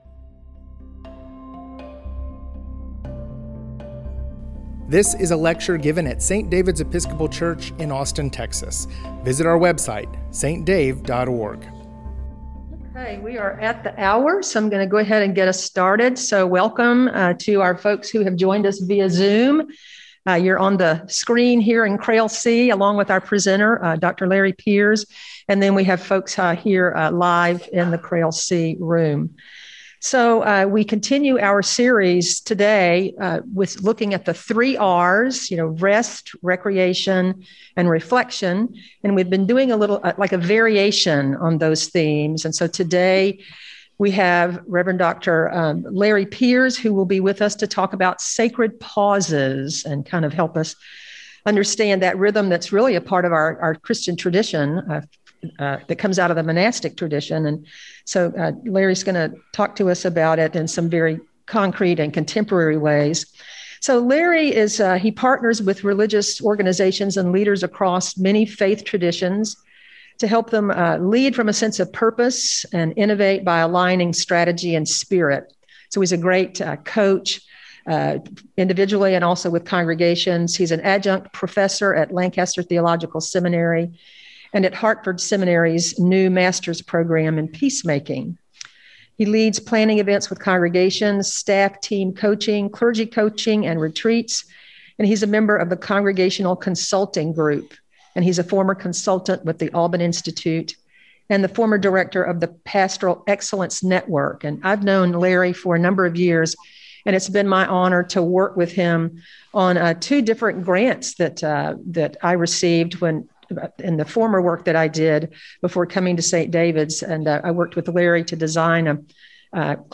Summer Lecture Series